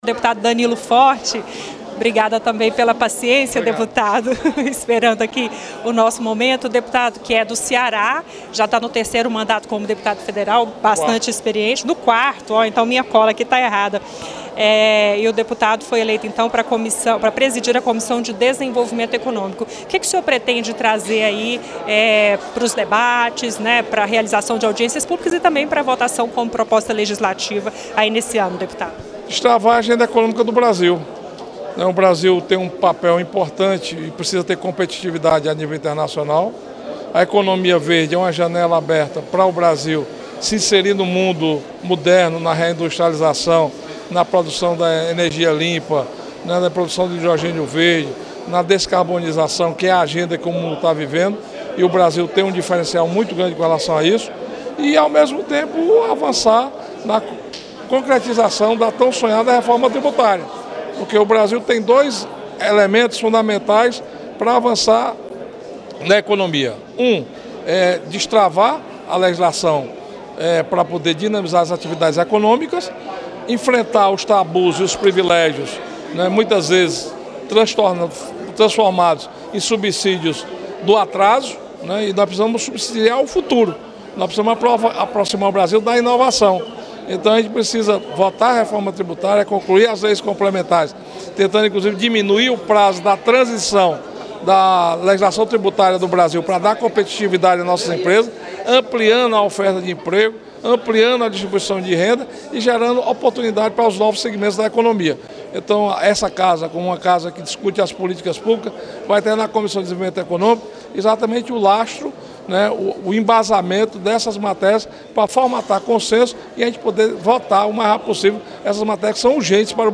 Eleito nesta quarta-feira(6) para comandar o colegiado, ele falou à equipe da Rádio e TV Câmara sobre como pretende conduzir os trabalhos na comissão. Segundo Danilo Forte, é preciso avançar neste ano na concretização da reforma tributária. Ele defende também a priorização da transição para uma economia verde.